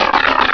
Cri de Mysdibule dans Pokémon Rubis et Saphir.